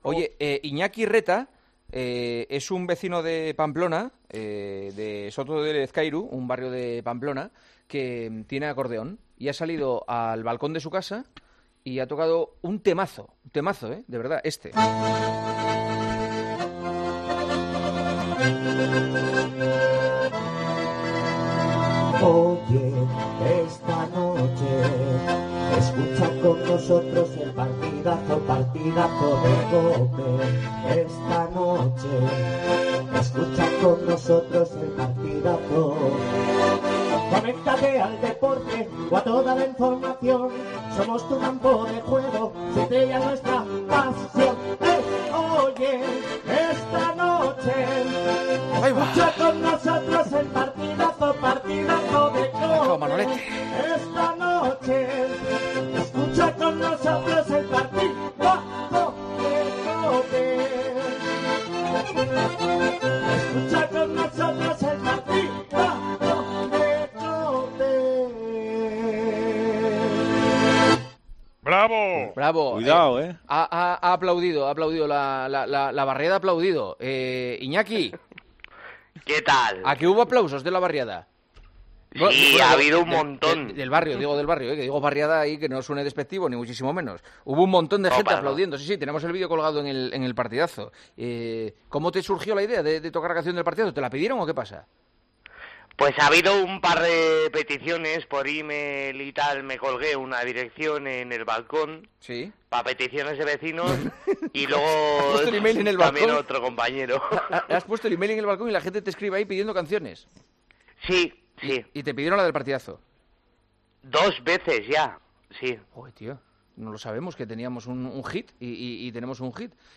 AUDIO: En Soto Lezkairu (Pamplona) un acordeonista interpreta a diario canciones en su terraza y elige entre las recomendaciones que le hacen sus vecinos.